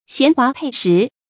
銜華佩實 注音： ㄒㄧㄢˊ ㄏㄨㄚˊ ㄆㄟˋ ㄕㄧˊ 讀音讀法： 意思解釋： 銜：包含；華：比喻文采；佩：佩帶；實：果實，比喻文章的思想內容。